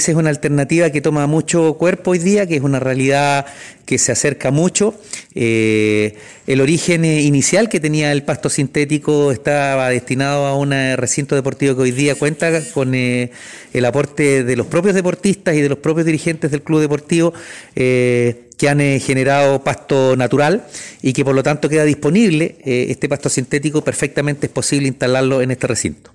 Alcalde-Oscar-Calderon-Reunion-con-clubes-Manuela-Figueroa-y-Rayon-Said-3.mp3